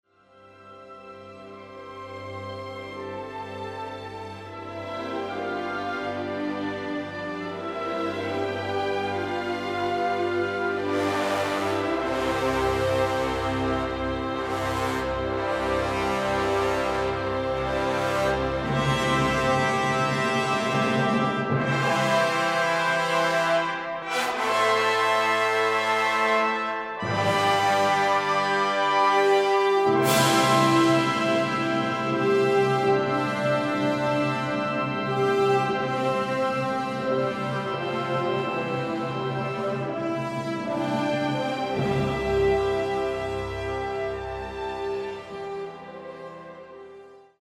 Musicians, don’t get distracted by the brass fanfare…the answer is in the melody.